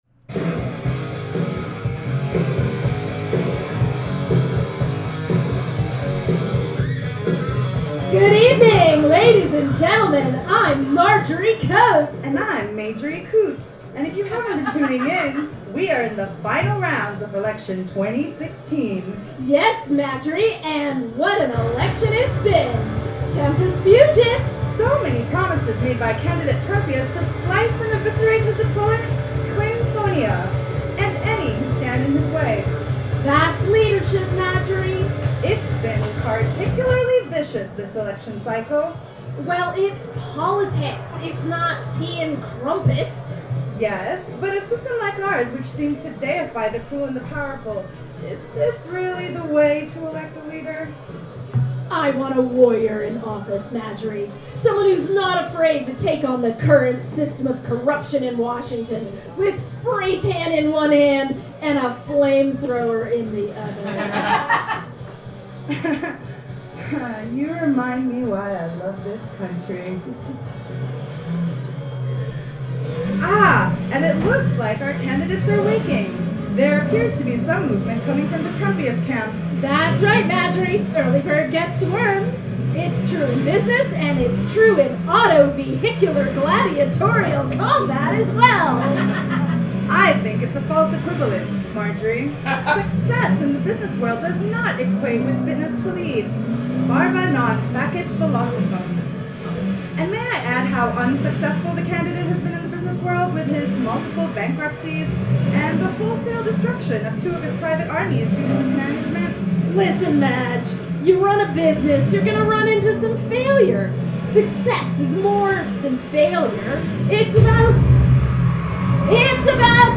performed live for Radio COTE: The Election, November 13, 2016 at Jimmy’s No. 43